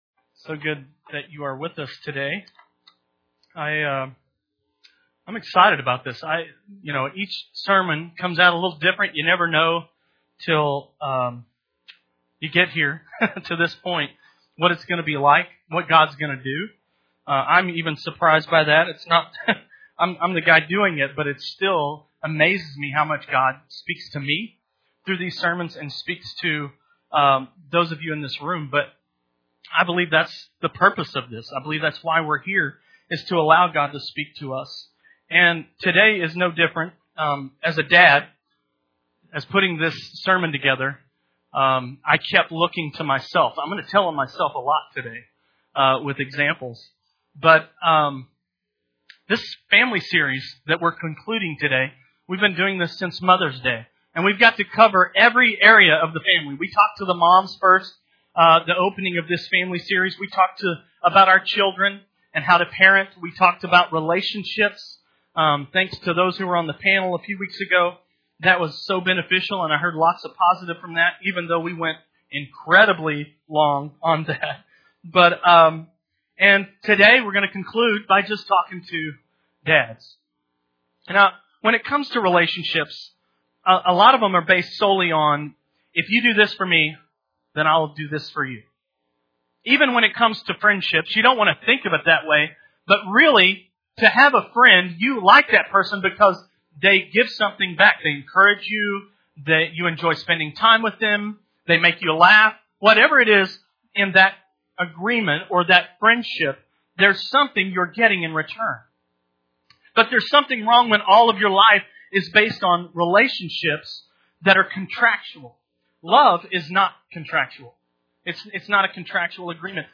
Family Sunday Morning Service
Sermon